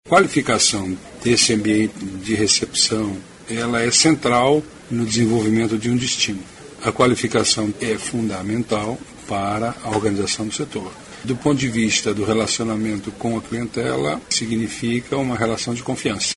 aqui e ouça fala do secretário Vinicius Lummertz sobre benefícios da regulamentação de negócios na área.